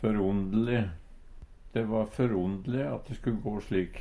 førrondele - Numedalsmål (en-US)